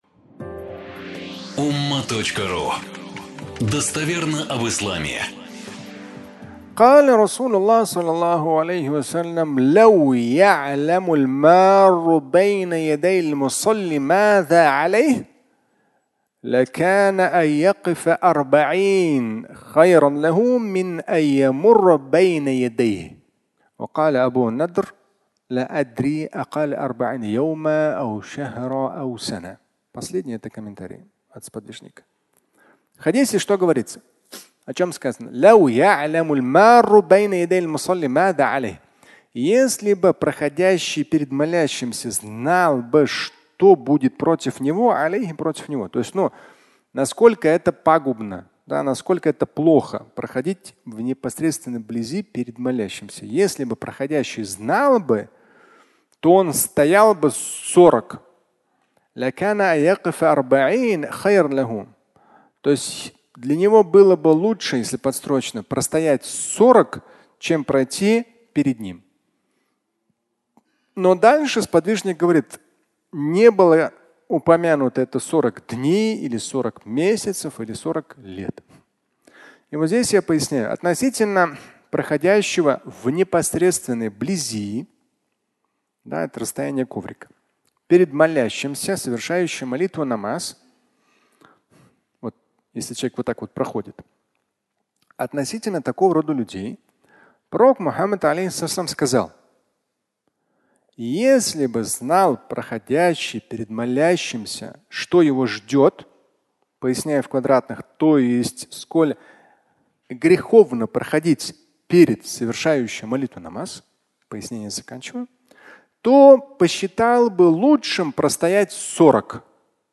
Ждать 40 (аудиолекция)
Пятничная проповедь